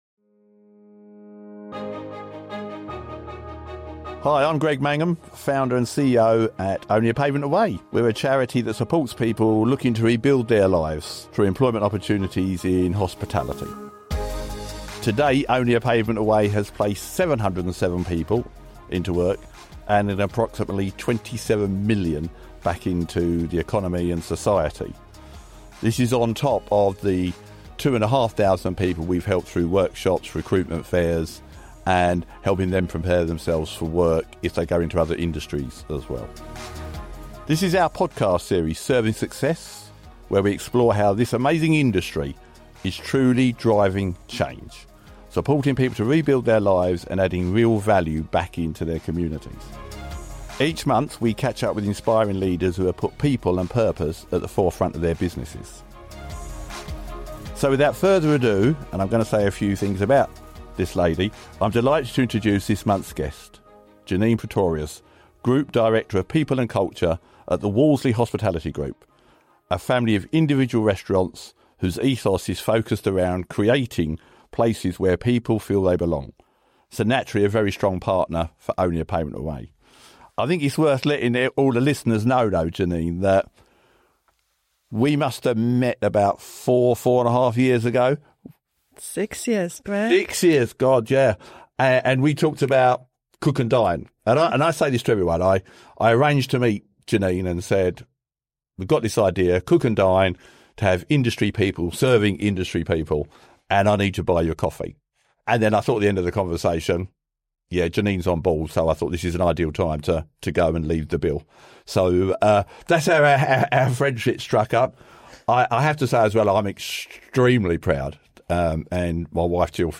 Each month, Serving Success: The Only A Pavement Away Podcast, shines a spotlight on purpose and positive change within the industry. Our hosts catch up with visionary industry leaders to discuss their own journey in hospitality, how they have driven forward environmental, social, and corporate governance in their businesses, and championed diversity & inclusion.